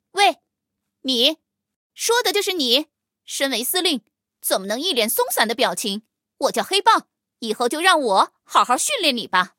黑豹登场语音.OGG